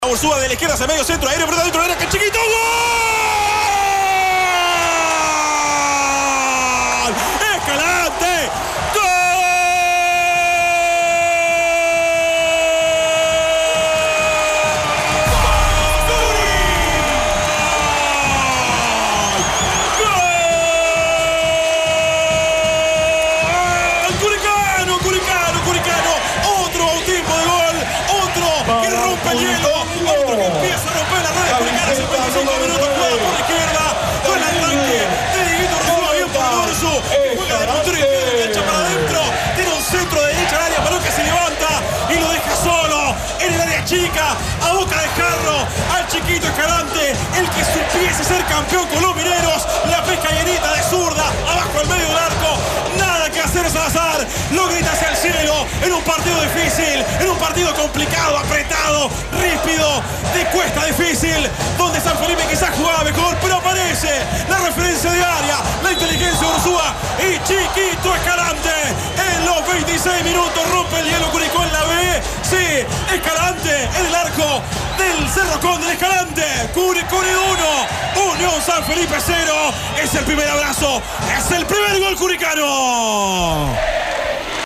[Audios] Así se relataron en Curicó los dos goles que abrieron el campeonato de Primera B 2024
El relato de los goles de Curicó Unido en el inicio de la Primera B 2024.